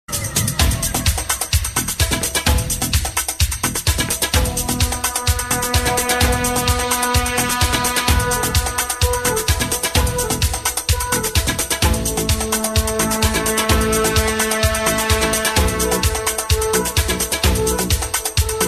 tabla-with-flute_24641.mp3